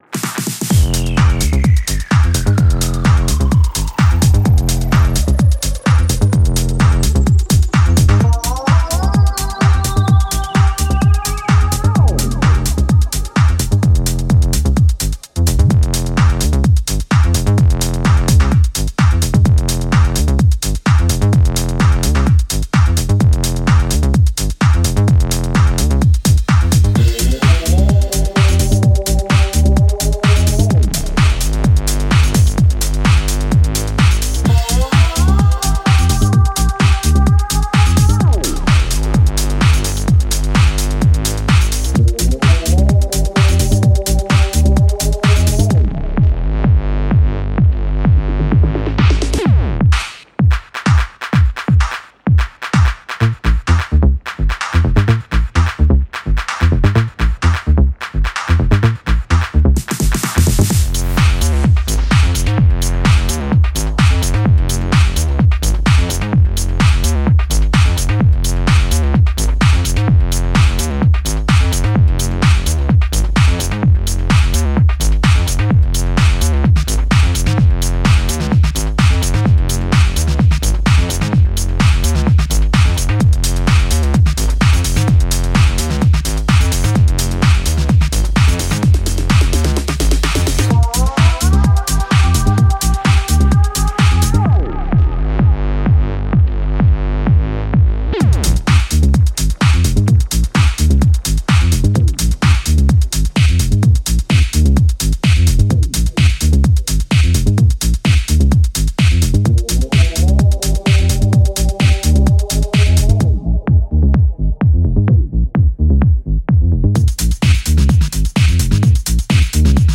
turbo-charged tech